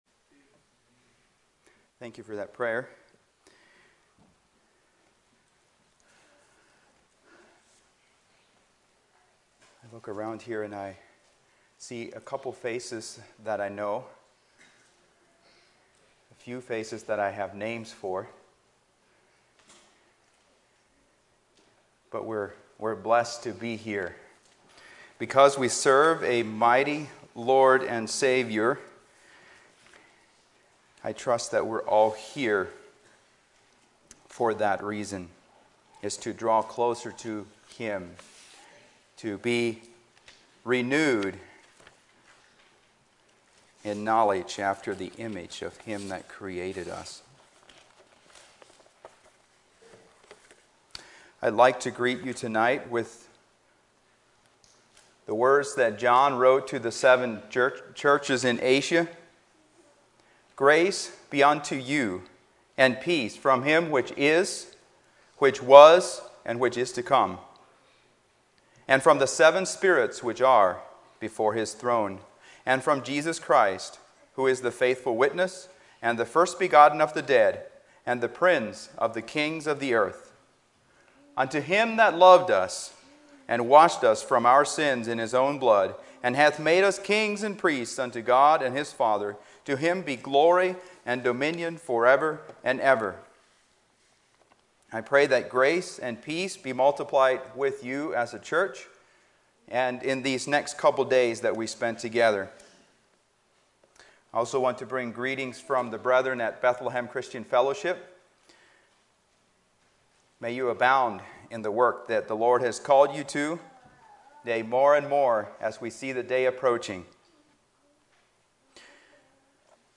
Youth Meeting Messages